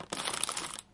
描述：部分来自原始录音，用zoom h2n拍摄。
Tag: 噼啪 裂纹 开裂 饼干 随机的 碾碎 紧缩 嘎吱嘎吱